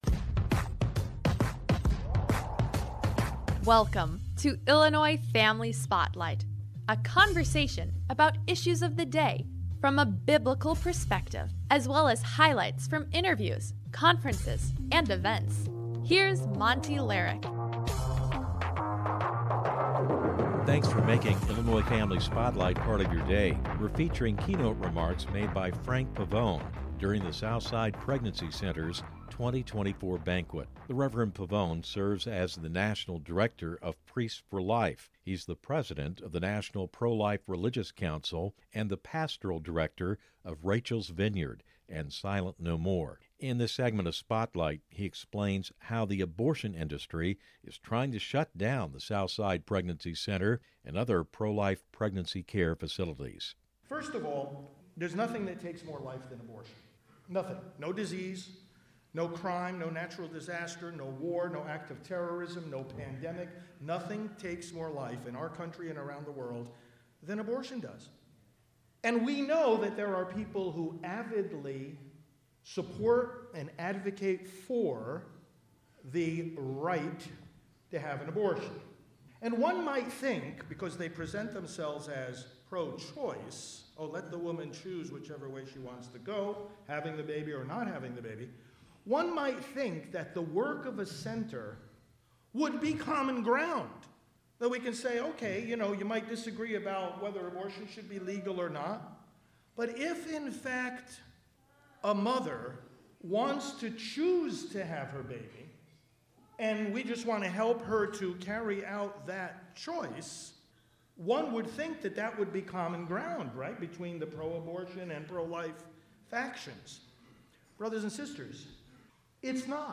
We are featuring keynote remarks given by Frank Pavone during Southside Pregnancy Center’s 2024 banquet. Rev. Pavone serves as the national director of Priests For Life and president of the National Pro-Life Religious Counsel and pastoral director of Rachel’s Vineyard.